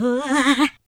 43 RSS-VOX.wav